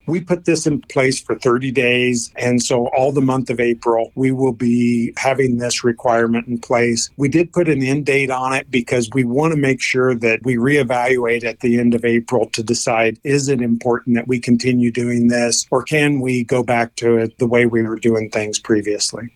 Dudley talks about how long the restrictions will be in effect.
Audio with Roger Dudley, Nebraska State Veterinarian